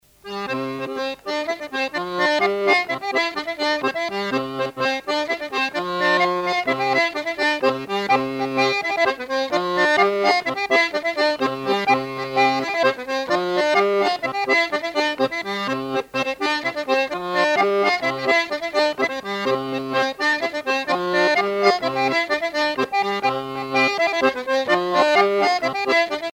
danse : riqueniée
Pièce musicale éditée